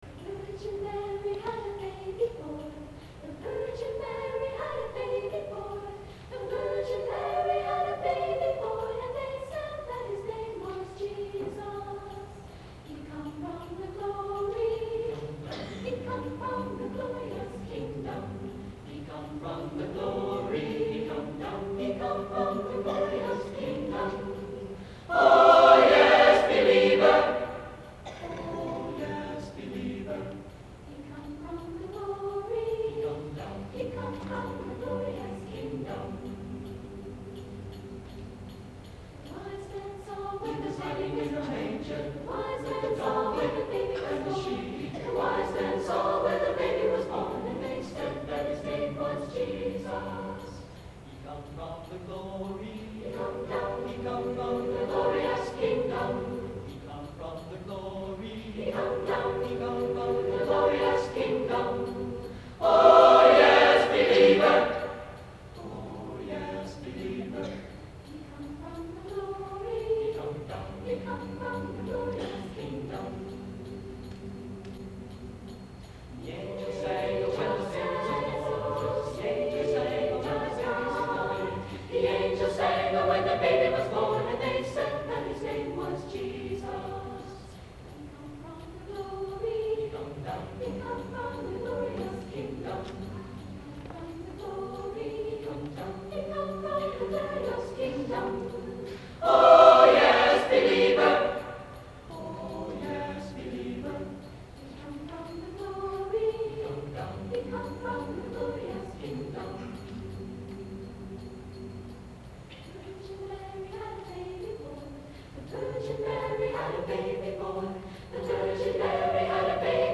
Christmas Concert 1972
Clay High Gym